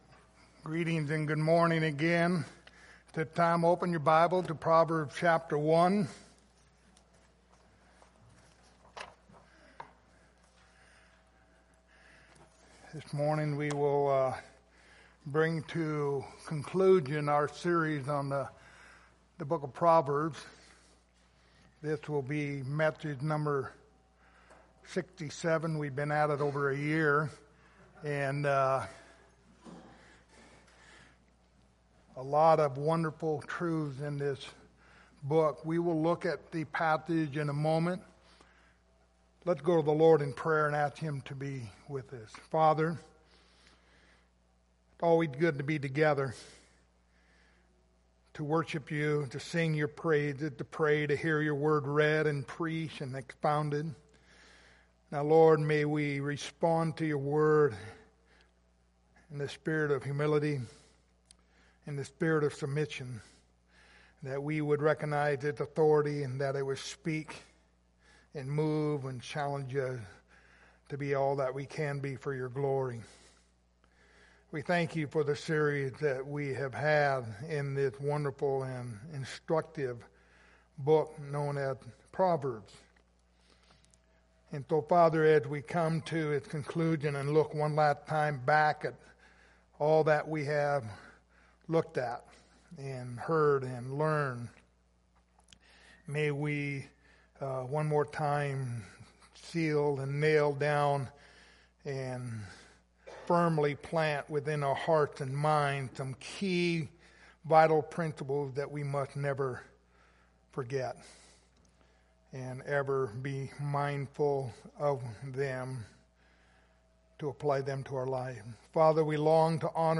Proverbs 1:2-6 Service Type: Sunday Morning Topics